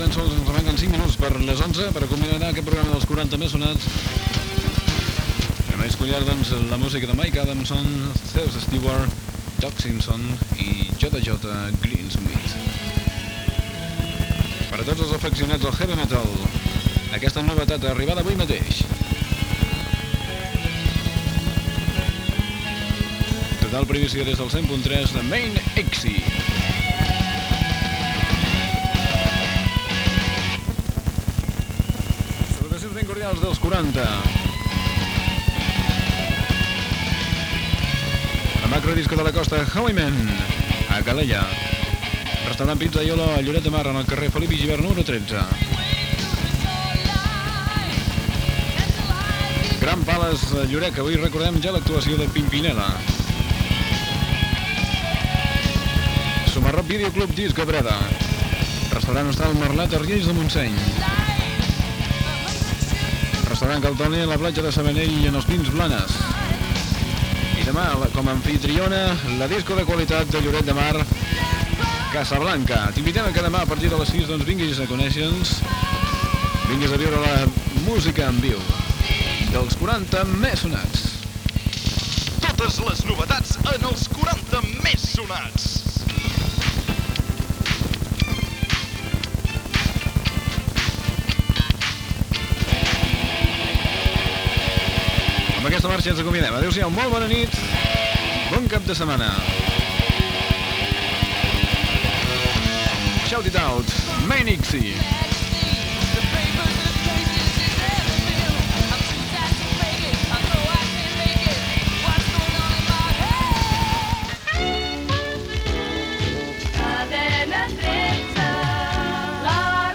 Tema musical, publicitat, indicatiu del programa, comiat, indicatiu Cadena 13, concurs per posar nom a un animal del Zoo de Barcelona, homenatge a Xesco Boix.
Musical
FM